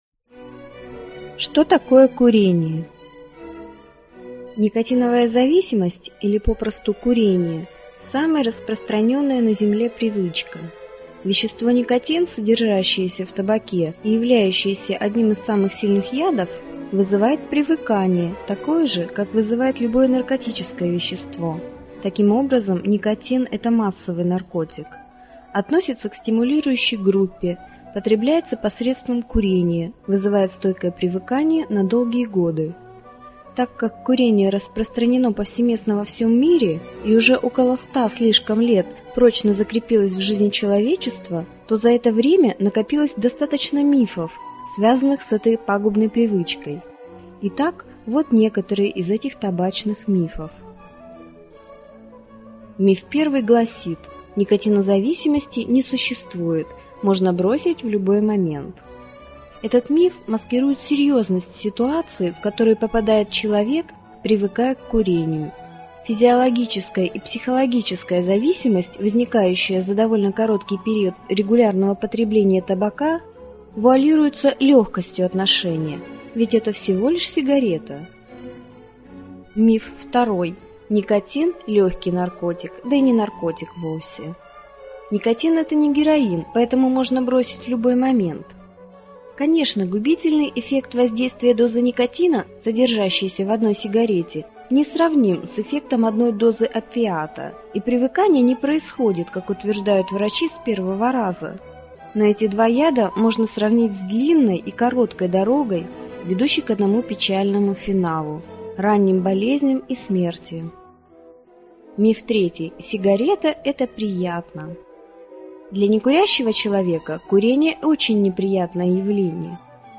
Аудиокнига Как бросить курить. Легко!